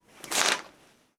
Correr unas cortinas 1
Sonidos: Acciones humanas
Sonidos: Hogar